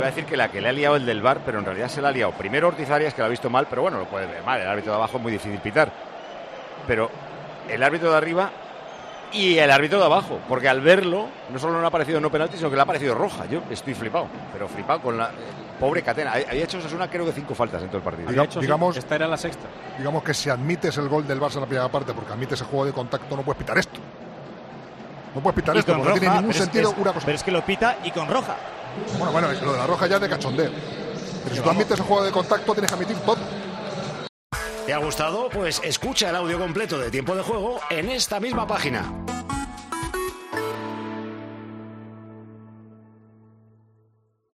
"¡Anda!", fue la reacción inmediata de Paco González, director de Tiempo de Juego, al ver al árbitro expulsar a Catena.